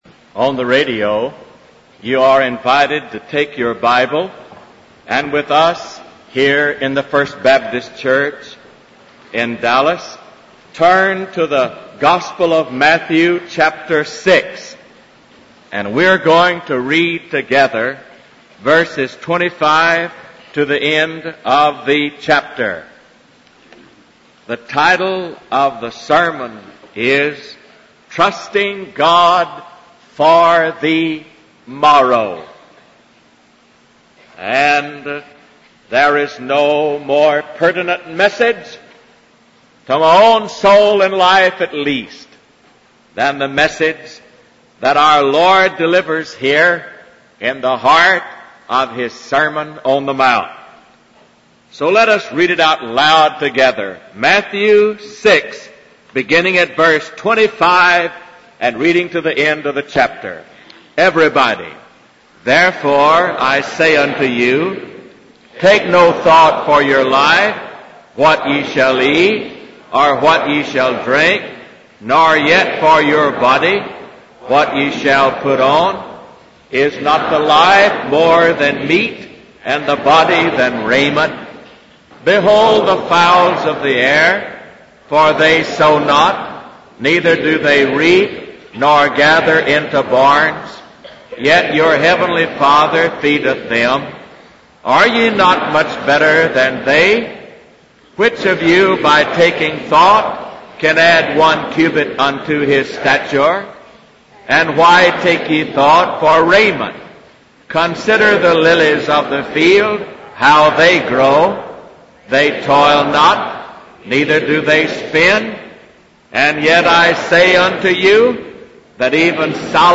W. A. Criswell Sermon Library | Trusting God for the Morrow